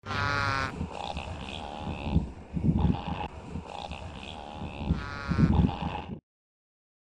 Звук газели в мире дикой природы